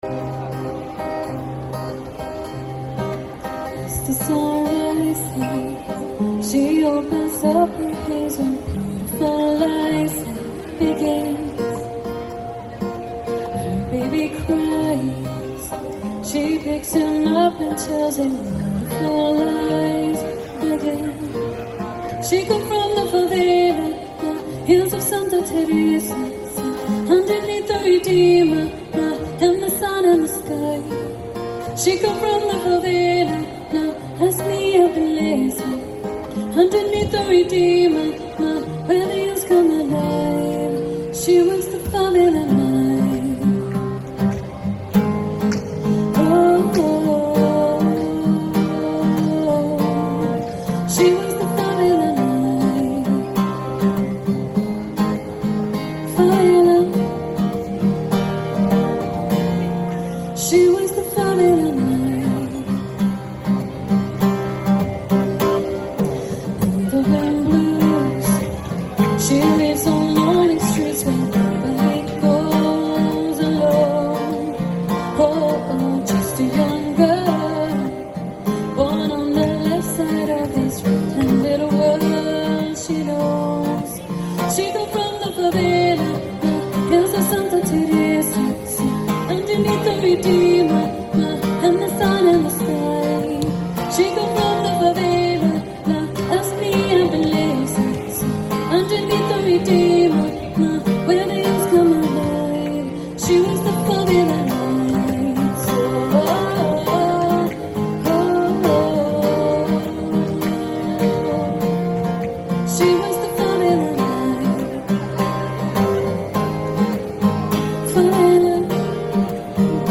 Musikduo